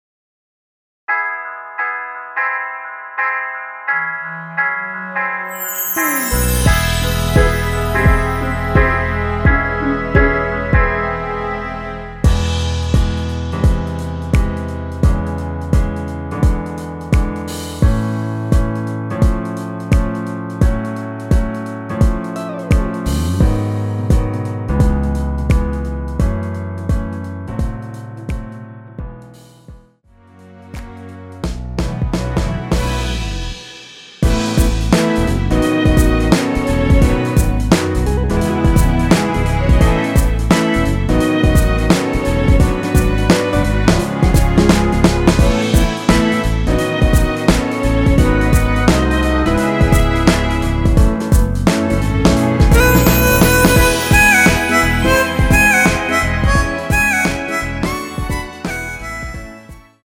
엔딩이 페이드 아웃이라서 노래하기 편하게 엔딩을 만들어 놓았으니 코러스 MR 미리듣기 확인하여주세요!
원키에서(-4)내린 MR입니다.
앞부분30초, 뒷부분30초씩 편집해서 올려 드리고 있습니다.
중간에 음이 끈어지고 다시 나오는 이유는